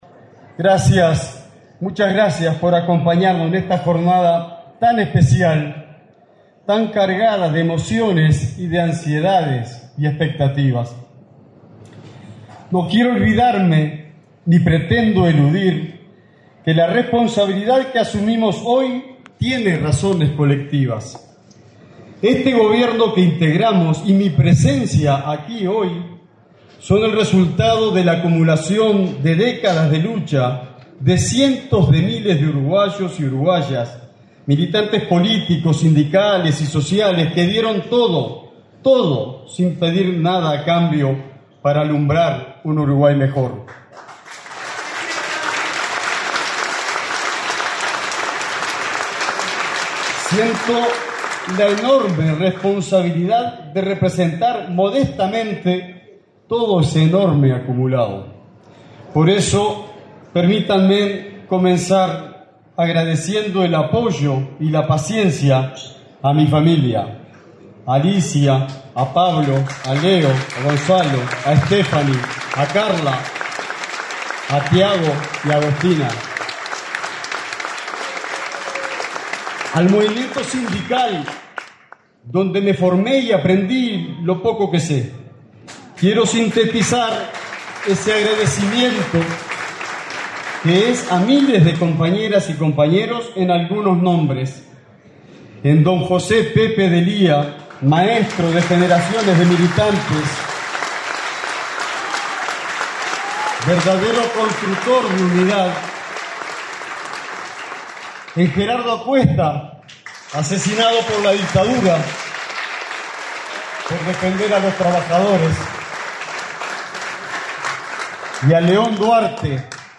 Palabras del ministro de Trabajo y Seguridad Social, Juan Castillo